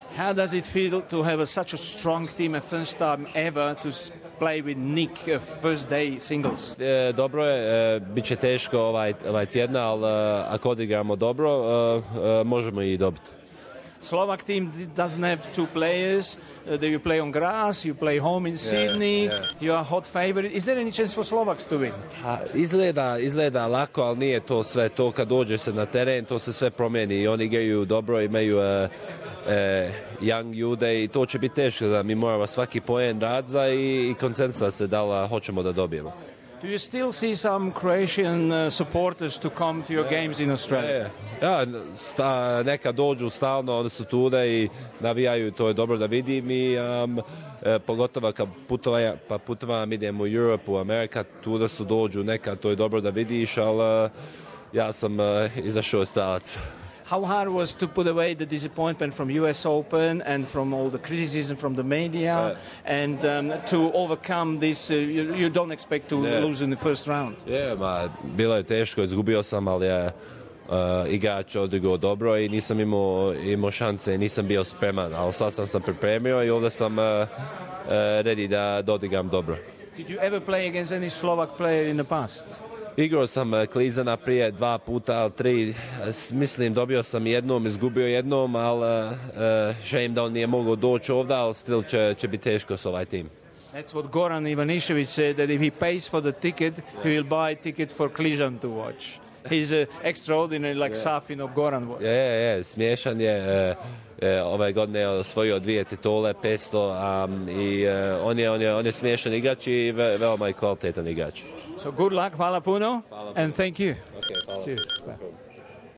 Bernard Tomić Interview